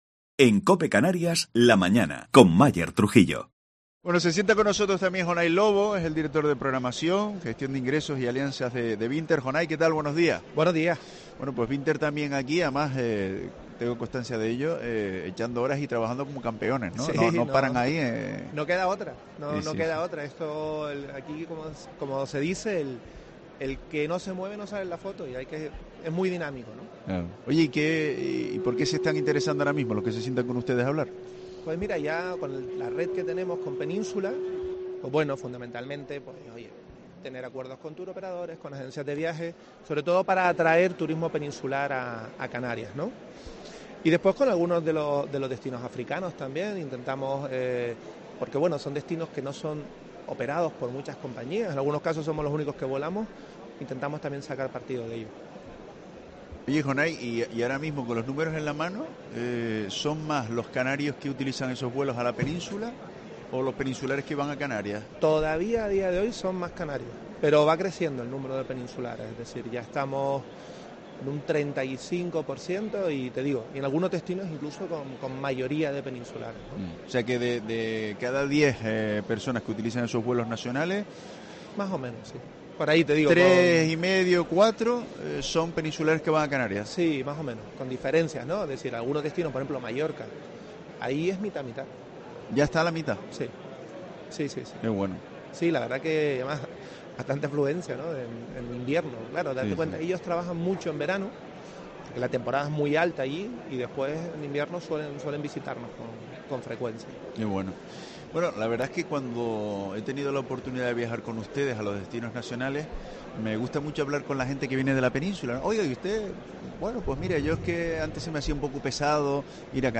FITUR 2023